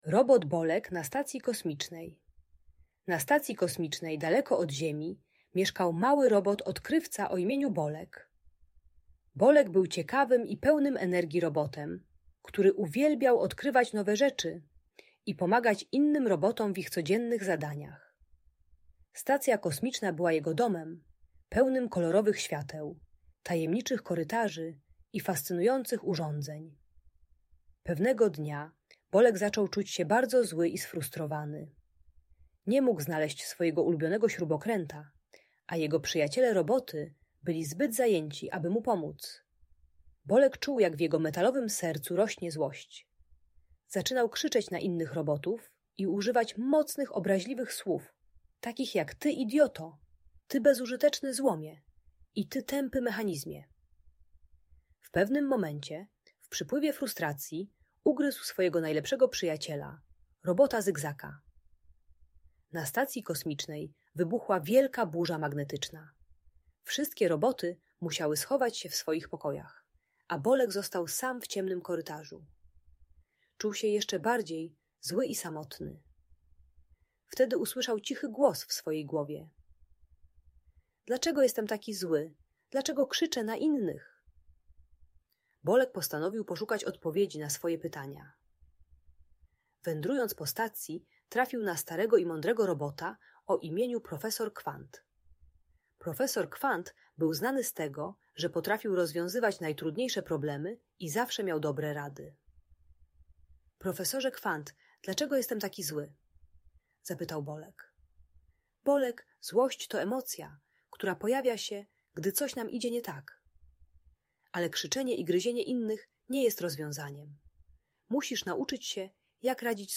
Historia Robota Bolka: Jak Radzić Sobie ze Złością - Audiobajka